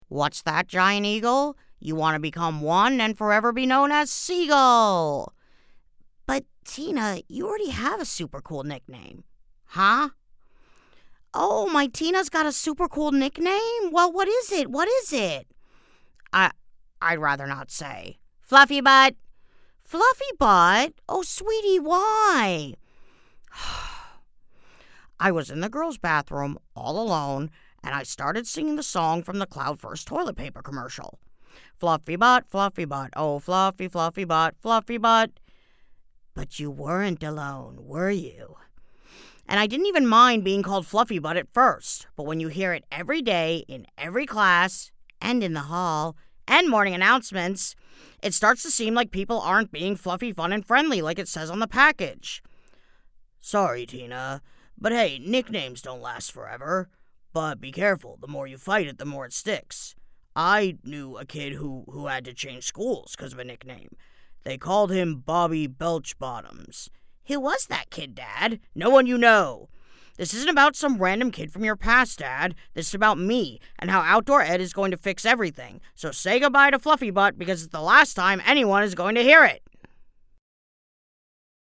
Character Demo
North American (native); New England, Texan, New York